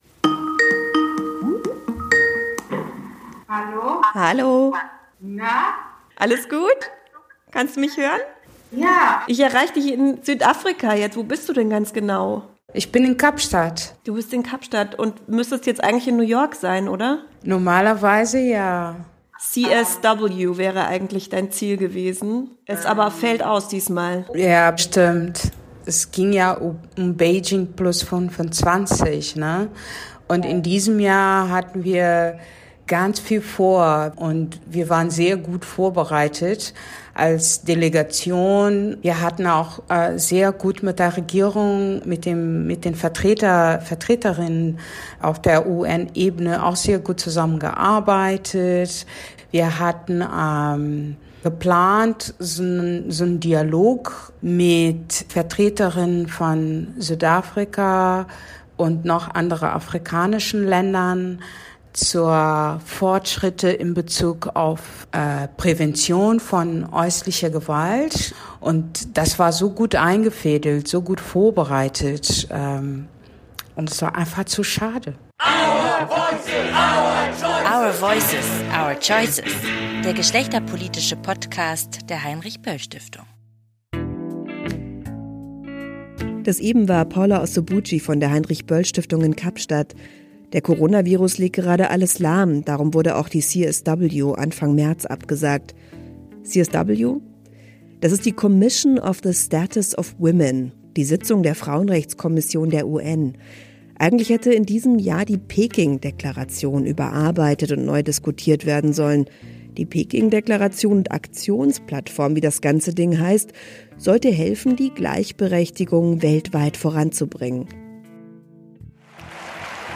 Wir haben mit Frauen aus Kambodscha, Kenia, Südafrika, Brasilien und Deutschland gesprochen und gefragt: Welche Rolle spielt Intersektionalität in ihrem Kampf um mehr Gleichberechtigung?